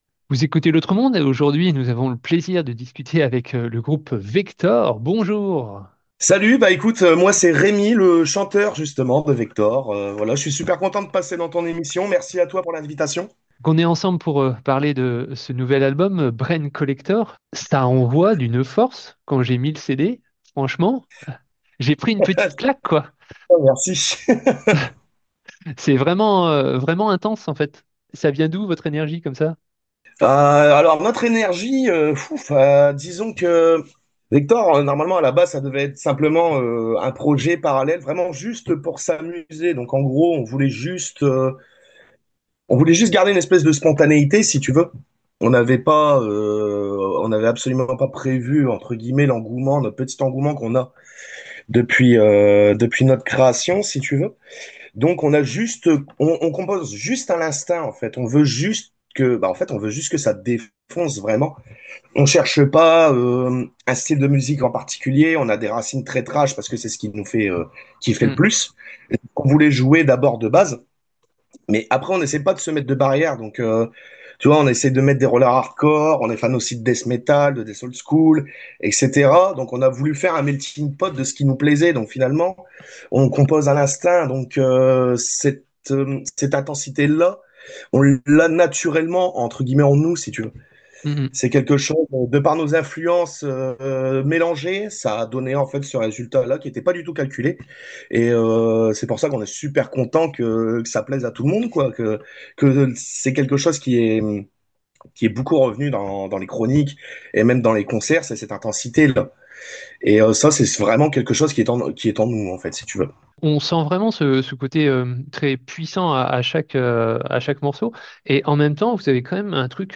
Pour télécharger l'interview : Itw.Vector.13.03.2026.mp3